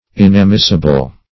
Search Result for " inamissible" : The Collaborative International Dictionary of English v.0.48: Inamissible \In`a*mis"si*ble\, a. [L. inamissibilis: cf. F. inamissible.] Incapable of being lost.